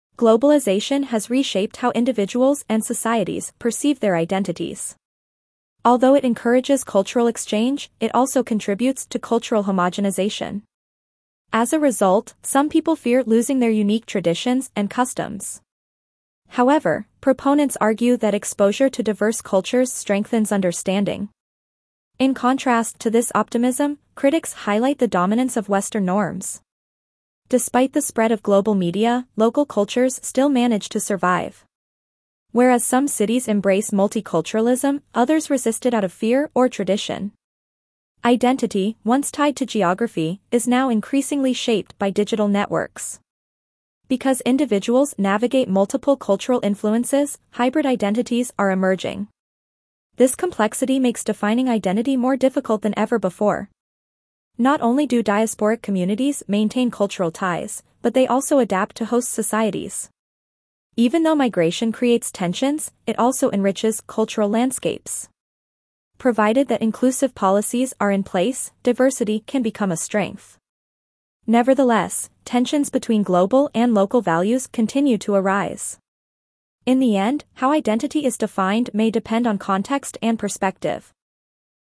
Your teacher will read the passage aloud.
2.-C1-Dictation-Globalization-and-Identity.mp3